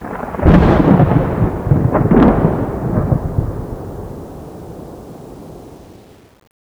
thunder-2.wav